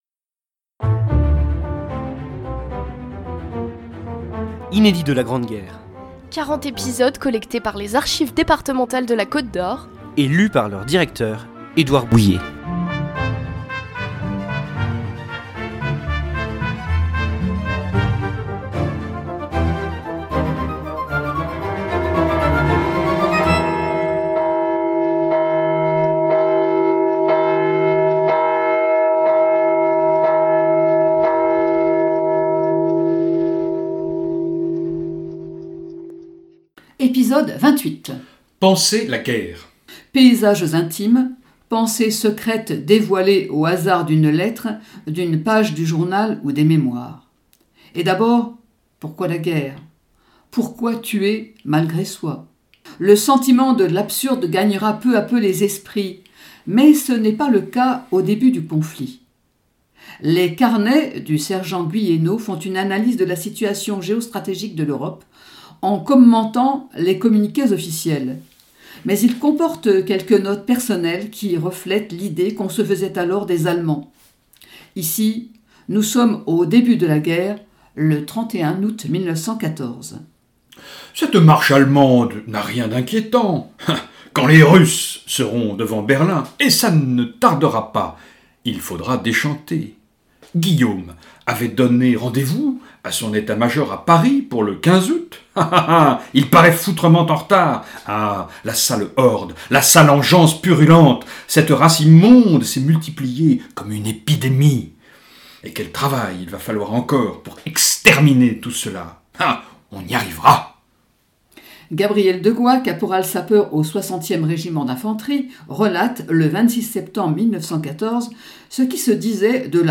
Un feuilleton de lettres et de journaux intimes à suivre jusqu’au 11 novembre 2018, pour célébrer le centenaire de l’armistice de la Grande Guerre, signé à Rethondes le 11 novembre 1918 !